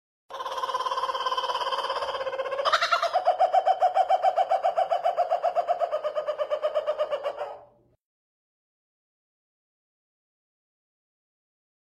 Pernah dengar suara beginian gak di tengah hutan..